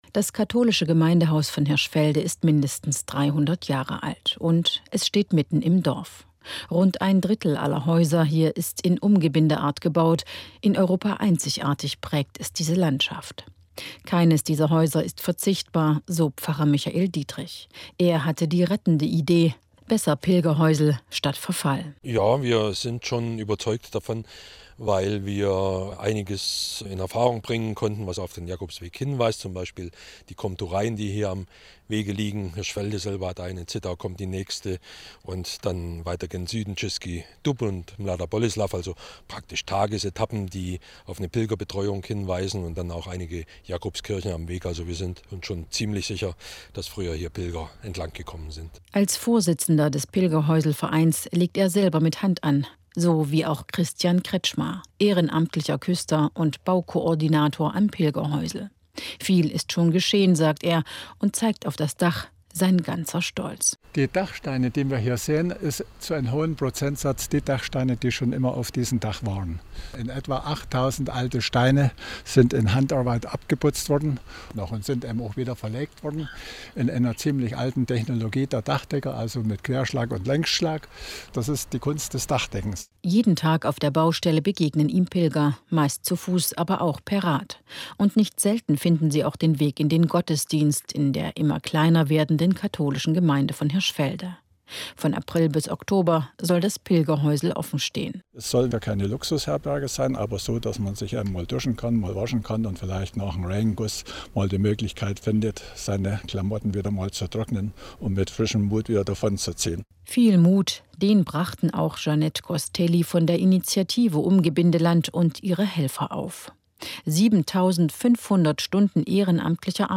Radiobeitrag als herunterladbare mp3-Datei (ca. 3.9 MByte)